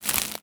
R - Foley 69.wav